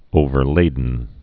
(ōvər-lādn)